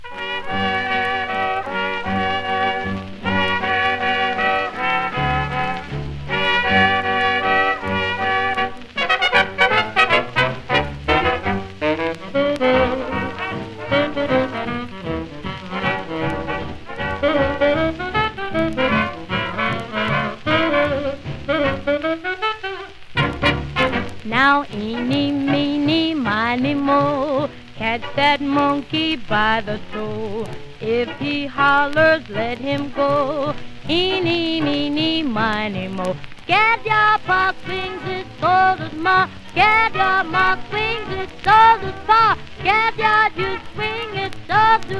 Jazz, Big Band, Vocal　USA　12inchレコード　33rpm　Mono